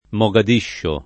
[ mo g ad & ššo ]